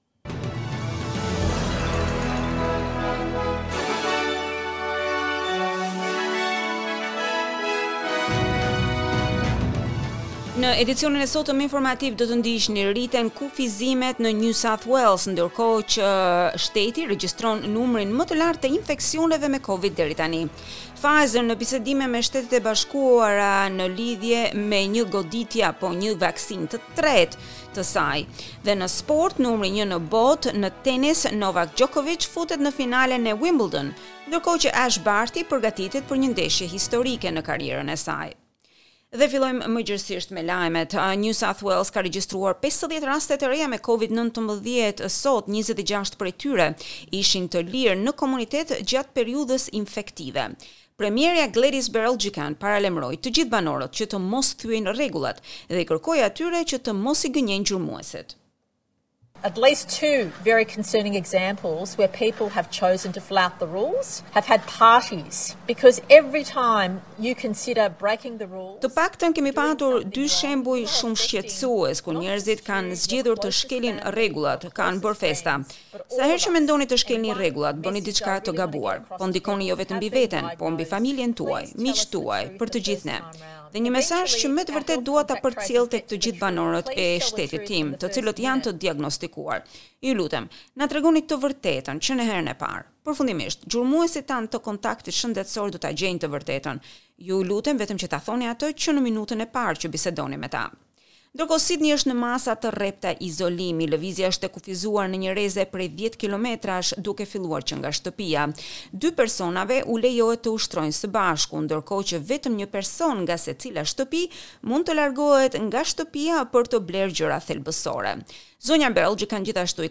SBS News Bulletin in Albanian - 10 July 2021